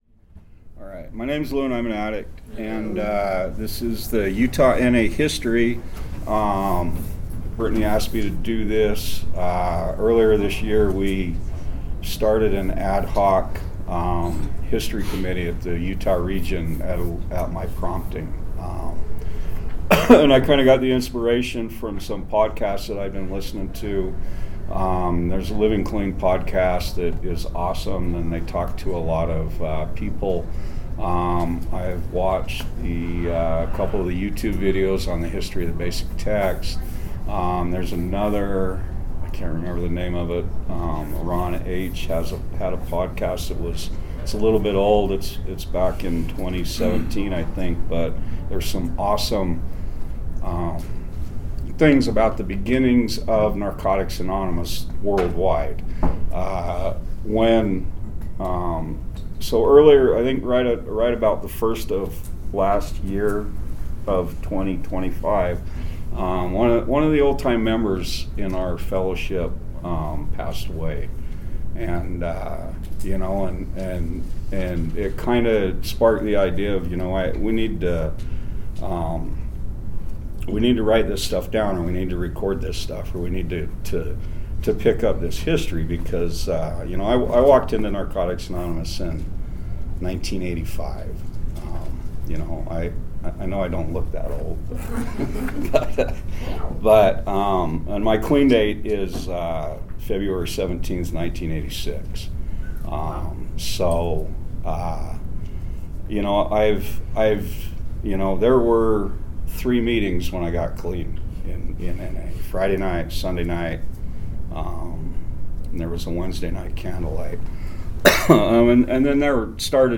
[In 2025 the Utah Region Indoor Convention began including a workshop with interviews with members involved in the early days of the NA Fellowship in Utah.]
Audio Interviews / Speaker Events [In 2025 the Utah Region Indoor Convention began including a workshop with interviews with members involved in the early days of the NA Fellowship in Utah.]